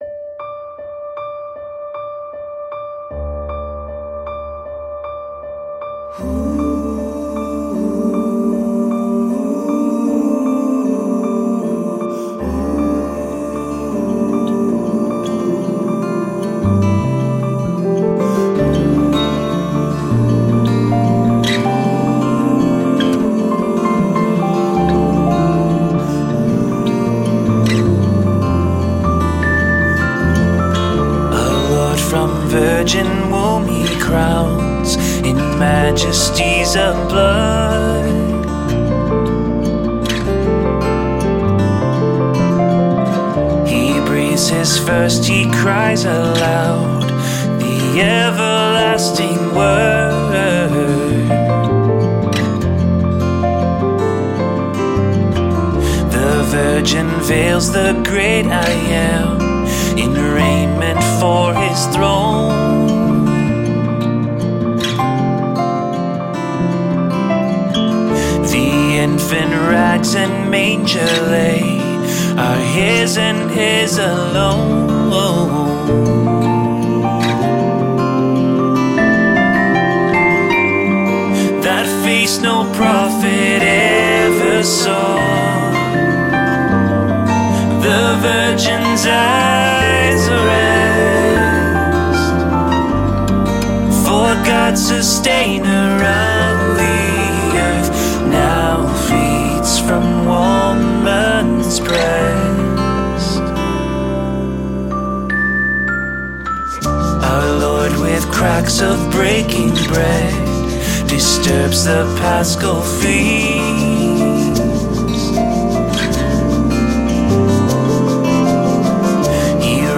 guitar
drums
piano